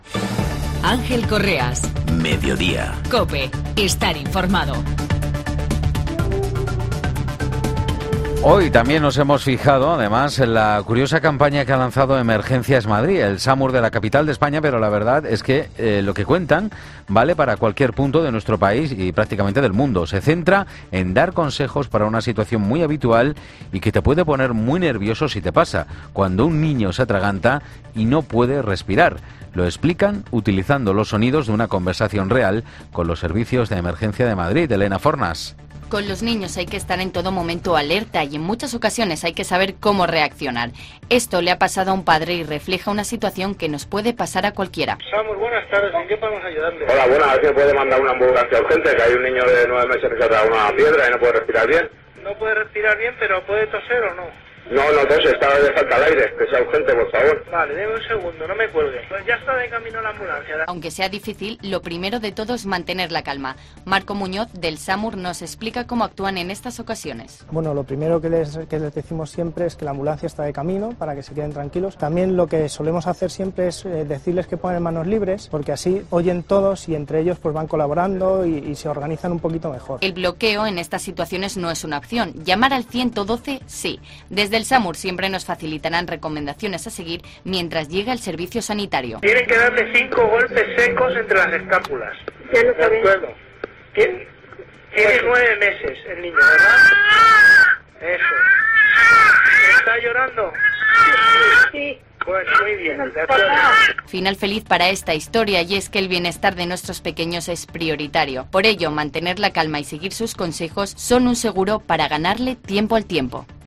Lo explican utilizando los sonidos de una conversación real con los servicios de emergencia.